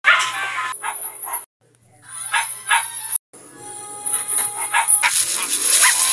Fifi Barking Sound Button - Free Download & Play